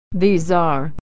Reading - Robert Frost - Authentic American Pronunciation
Linking: